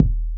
25 kick hit.wav